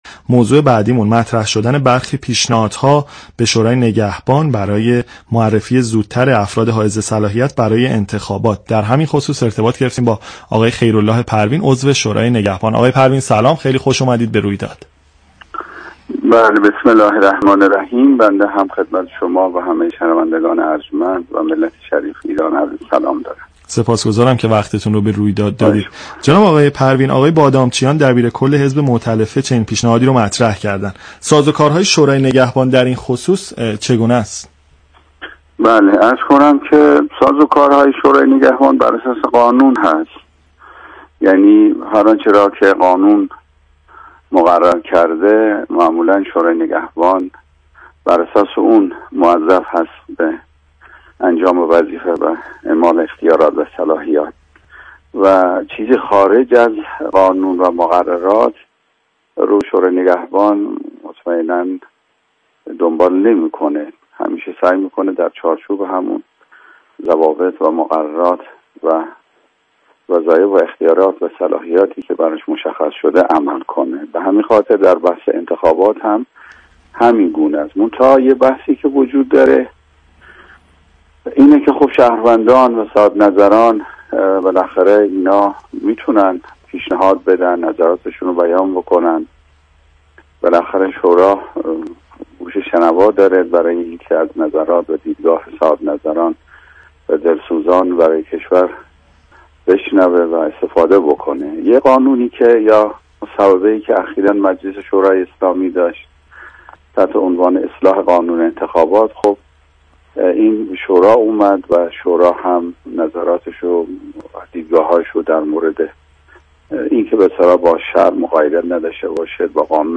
دکتر پروین در مصاحبه با «رادیو گفتگو» :